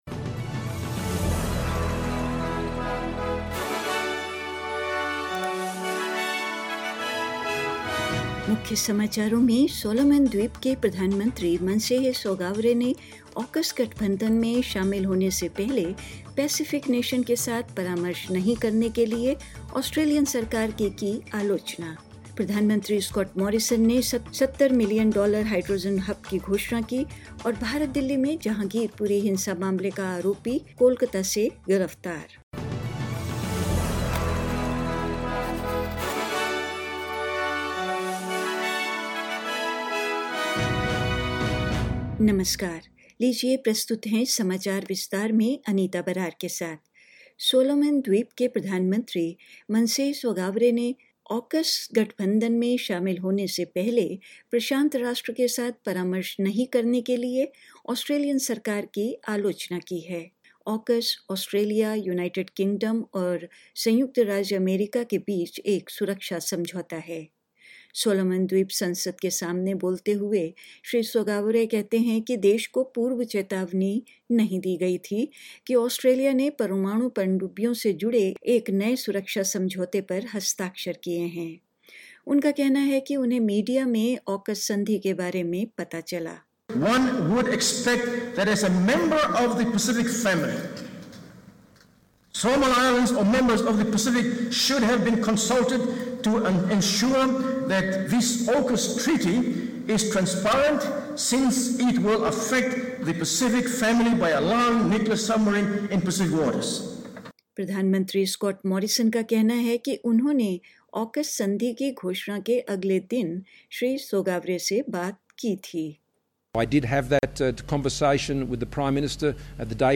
In this latest SBS Hindi bulletin: Solomon Islands Prime Minister Manasseh Sogavare criticises the Australian government for not consulting with the Pacific nation before joining the AUKUS alliance; Prime Minister Scott Morrison announces a $70 million hydrogen hub; Rugby League officials threaten to take the NRL Grand Final out of Sydney and more news.